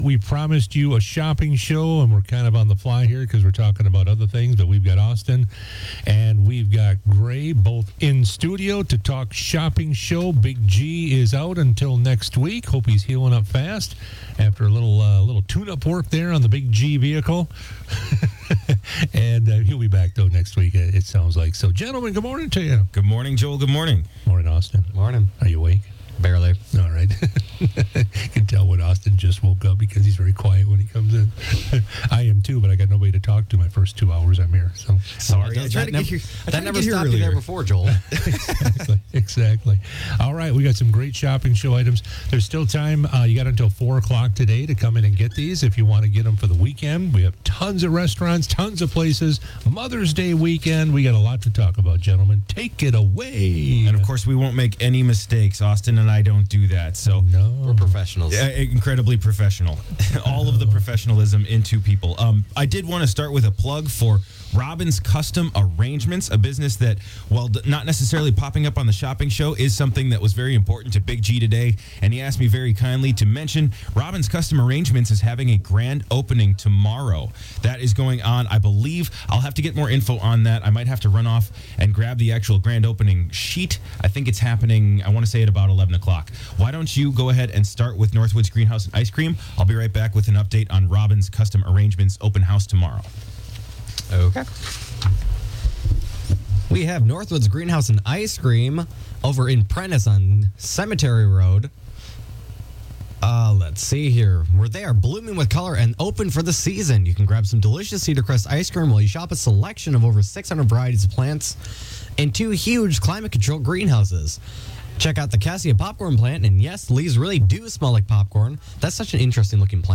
Interviews and special broadcasts from 98Q Country in Park Falls.